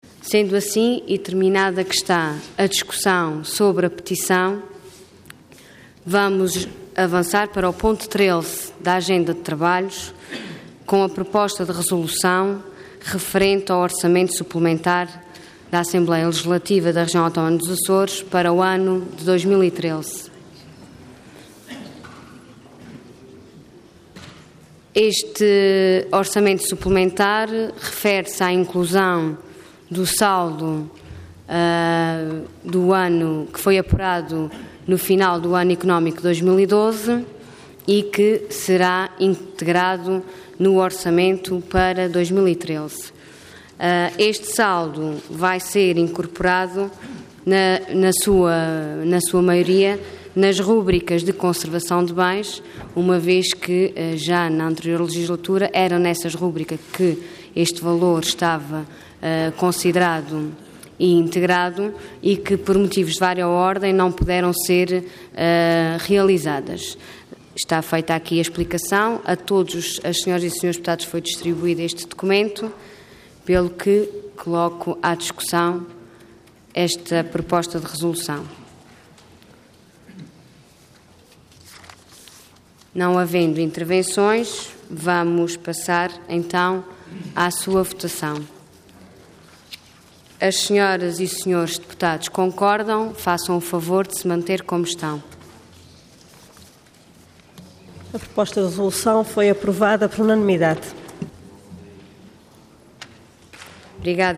Intervenção Proposta de Resolução Orador Ana Luísa Luís Cargo Presidente da Assembleia Regional Entidade Mesa da Assembleia